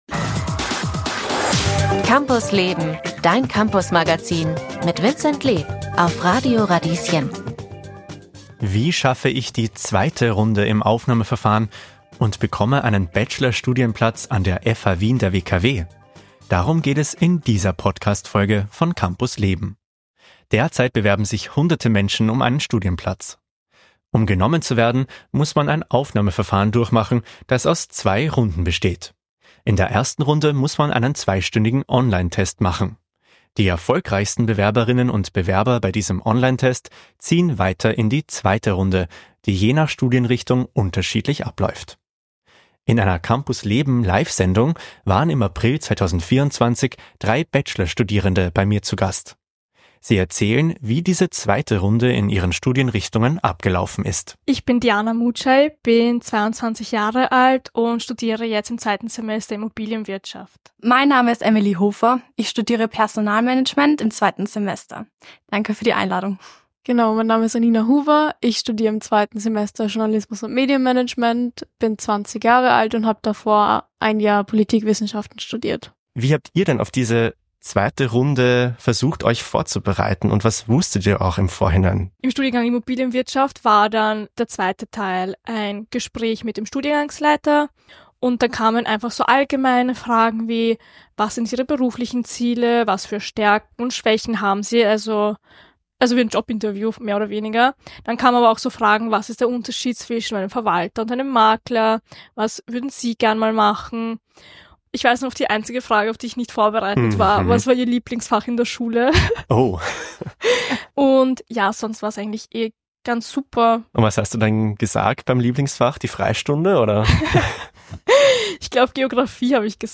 In dieser Podcast-Folge erzählen drei BA-Studierende im 2. Semester, wie sie die zweite Runde des Aufnahmeverfahrens erfolgreich hinter sich gebracht haben.
Die zweite Runde ist von Studiengang zu Studiengang unterschiedlich. Die Ausschnitte stammen aus einer Live-Sendung vom April 2024.